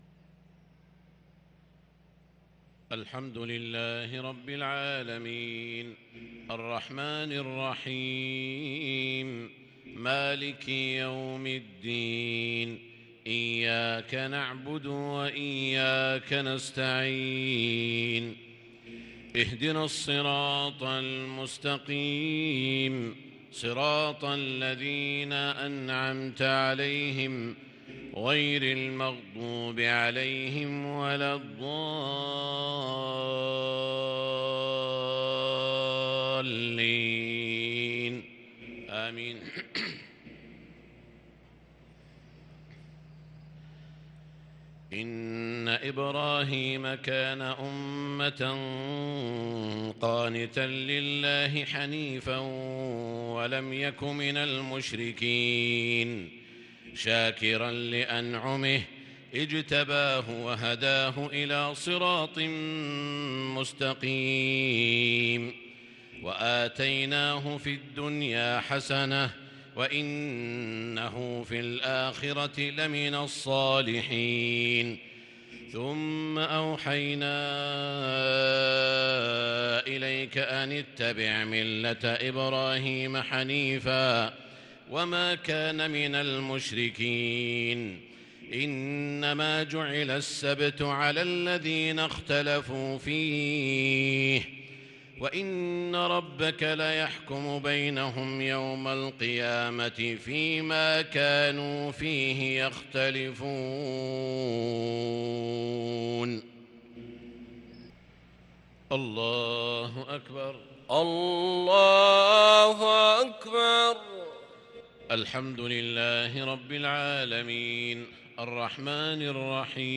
صلاة المغرب للقارئ سعود الشريم 12 ربيع الأول 1444 هـ
تِلَاوَات الْحَرَمَيْن .